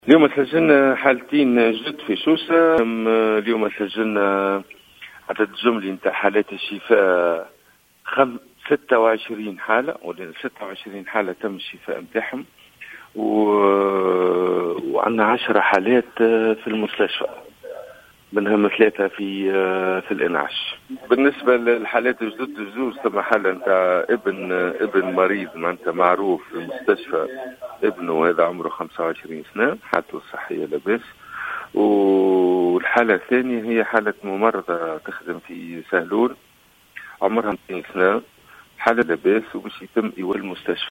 أكد المدير الجهوي للصحة بسوسة، في تصريح للجوهرة أف أم، اليوم الأحد تسجيل إصابتين جديدتين بفيروس كورونا في الجهة.